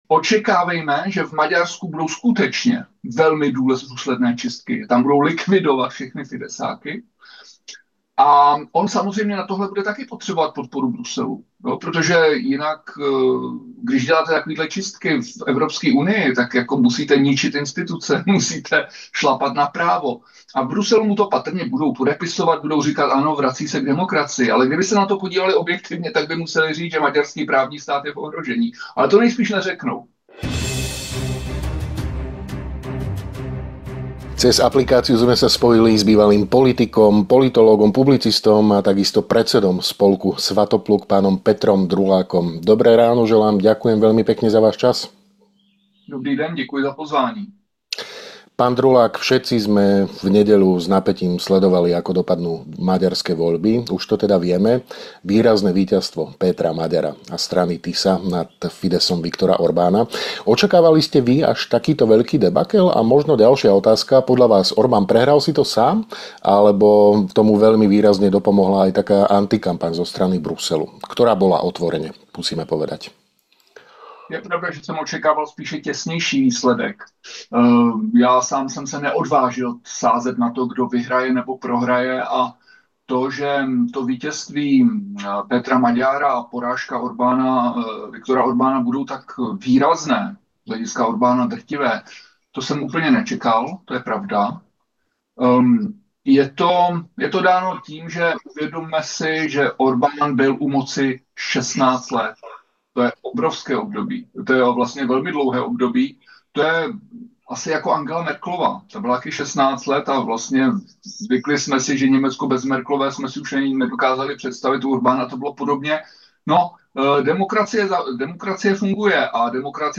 V rozhovore pre Hlavné správy sa venuje aj širšiemu obrazu stredoeurópskej spolupráce, ktorú vidí v ohrození vďaka Tuskovi, ako aj nastupujúcemu Magyrovi. Robert Fico podľa neho má potenciál prevziať Orbánovu rolu hlasu zdravého rozumu, ale jeho pozícia bude mimoriadne náročná.